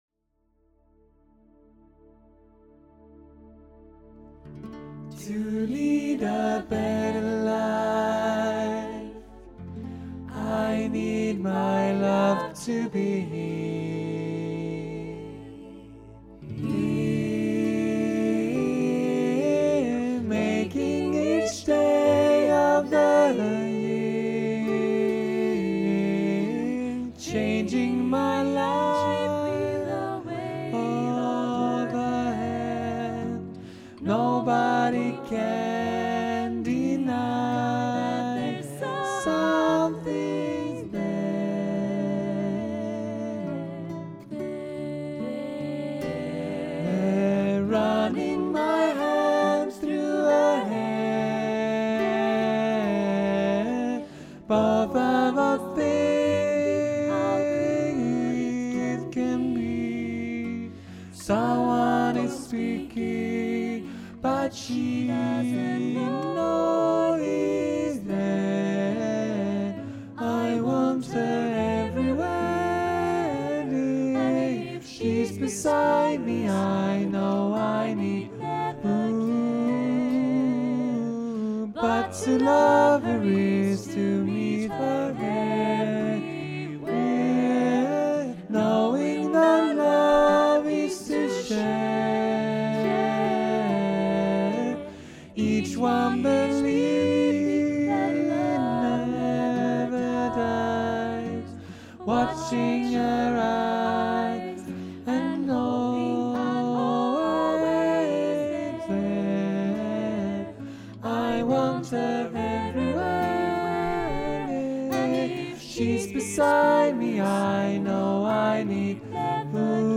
för 4-stämmig blandad kör